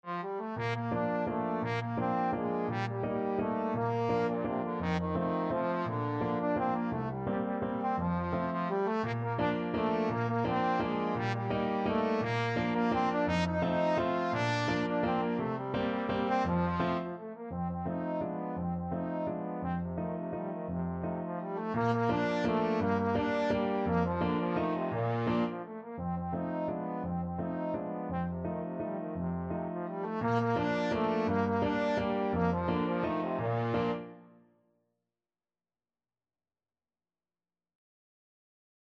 3/4 (View more 3/4 Music)
Classical (View more Classical Trombone Music)